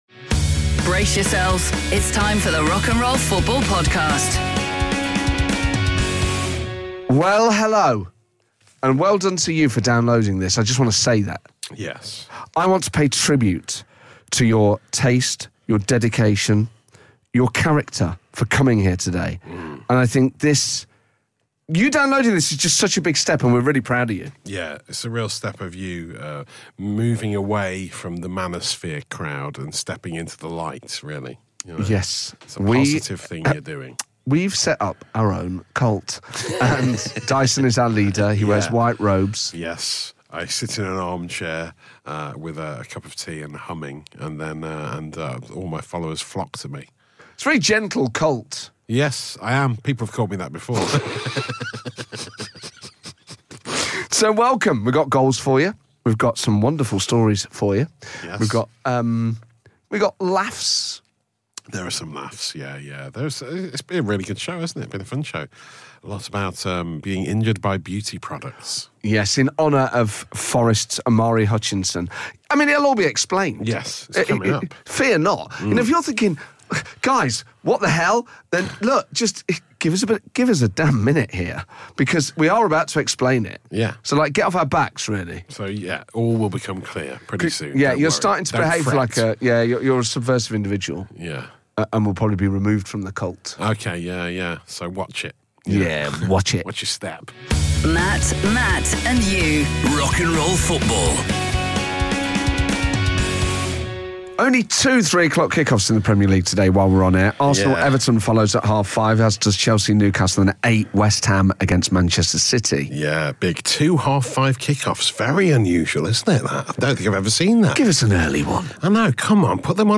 This week, the guys are joined on the line by new Chairman of Sheffield FC and lead singer of Reverend and the Makers, Jon McClure to chat about his new venture - and they ask you, after Omari Hutchinson got face cream in his eye while playing for Forest in midweek, have you ever been injured by a beauty product?